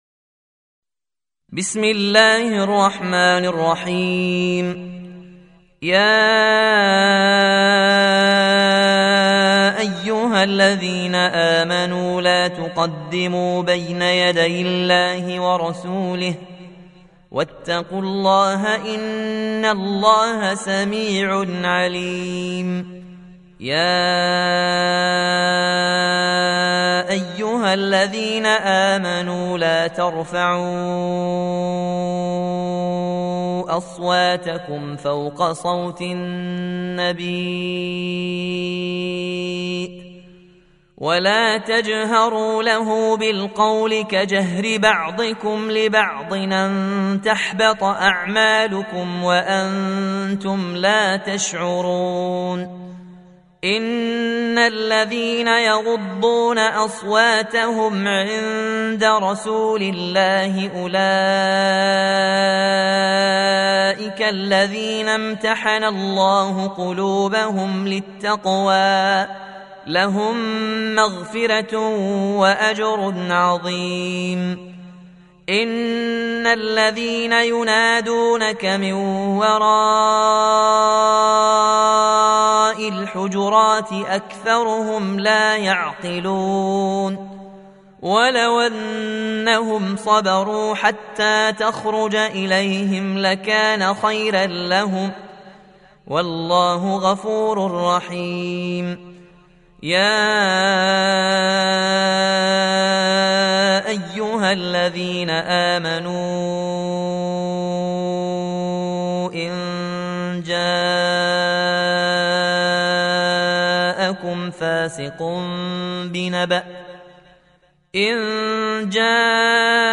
Surah Sequence تتابع السورة Download Surah حمّل السورة Reciting Murattalah Audio for 49. Surah Al-Hujur�t سورة الحجرات N.B *Surah Includes Al-Basmalah Reciters Sequents تتابع التلاوات Reciters Repeats تكرار التلاوات